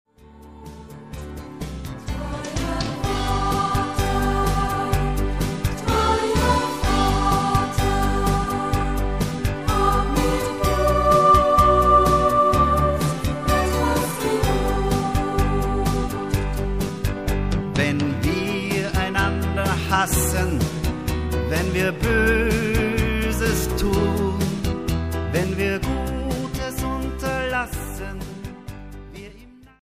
eine populäre Messe